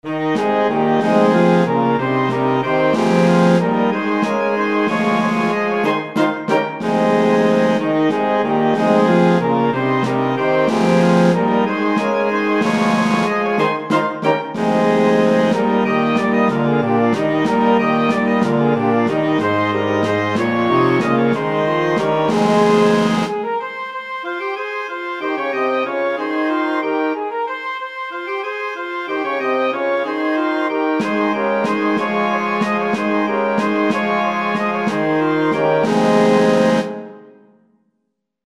Kolędy Znaczniki